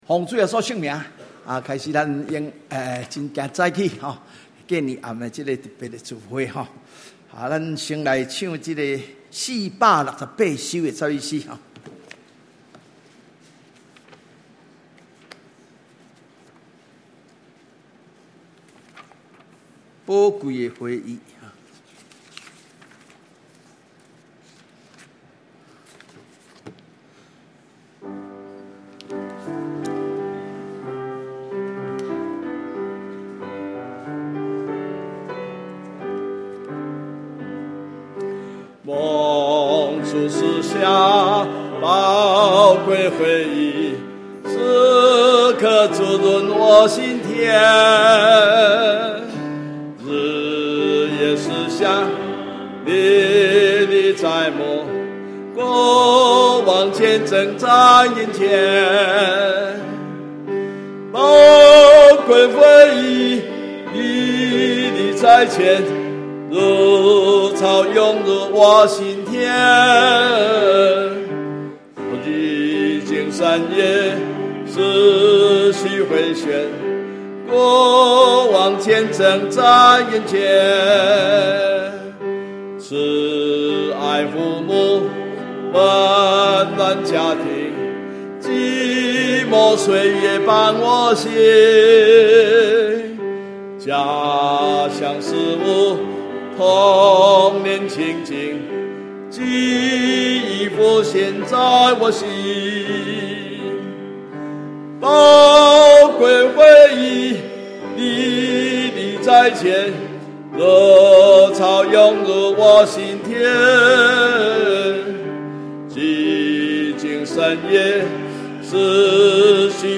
除夕特別聚會